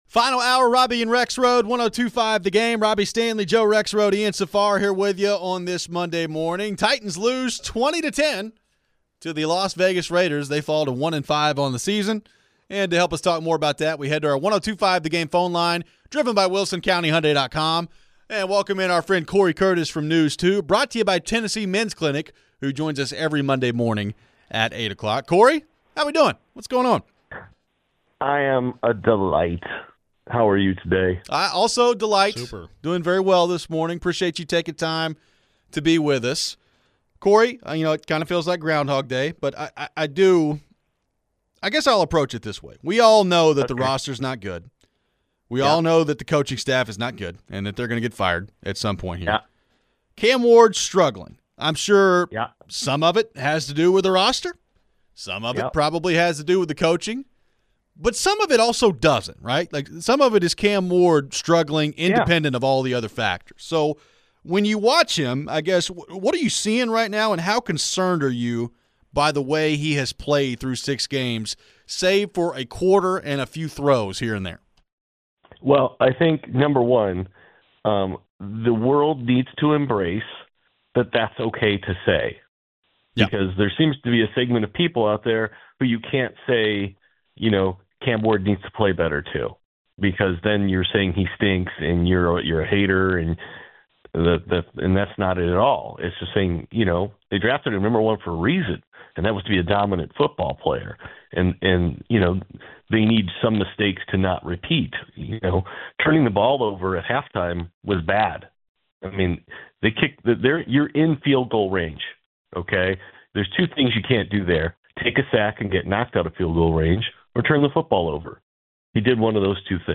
Interview
Why do we think the Titans failed to carry over the momentum from the Arizona win? We take your phones.